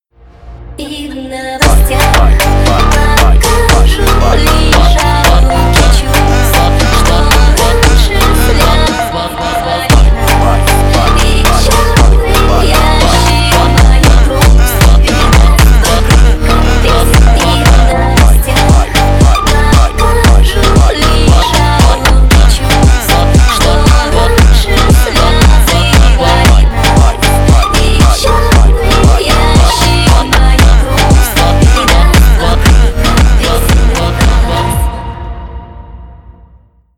Рингтоны на звонок
Нарезка припева на вызов